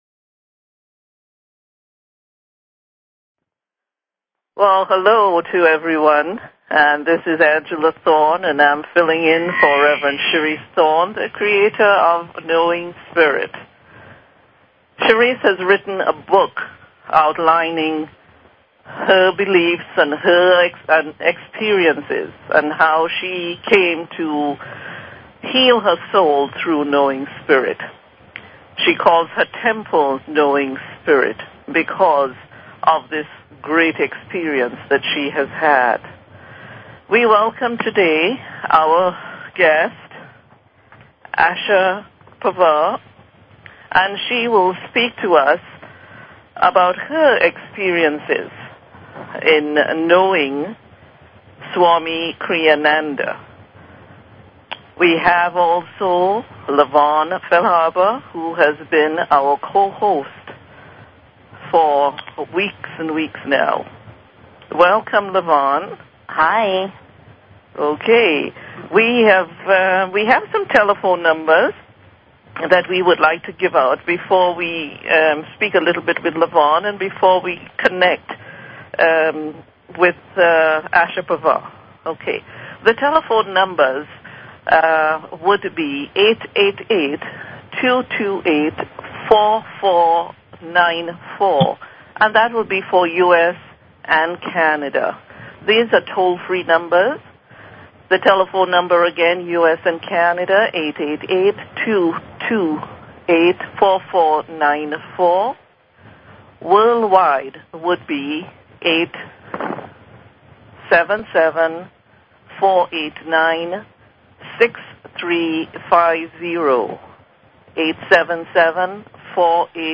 Talk Show Episode, Audio Podcast, Knowing_Spirit and Courtesy of BBS Radio on , show guests , about , categorized as